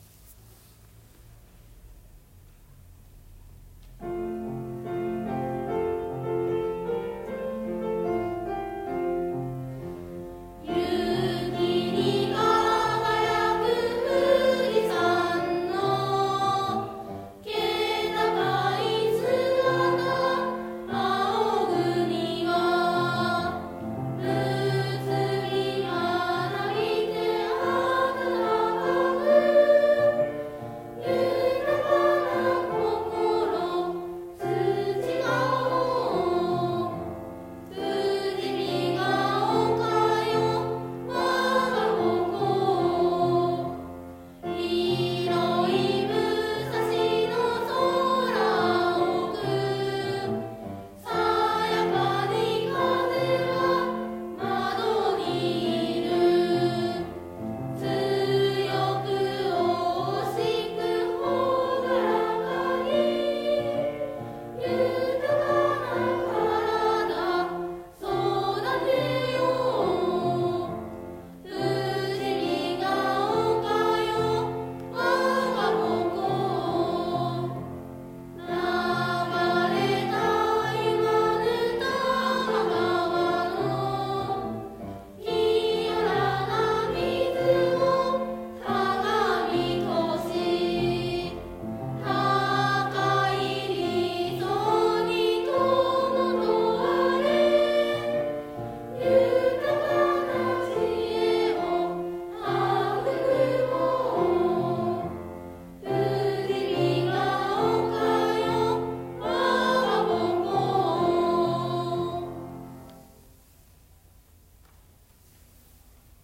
卒業式で流した
「校歌」は、
「旅立ちの日に」と同様、ほとんど練習もできませんでしたが、気持ちのこもった美しい歌声でした（いずれも録音）。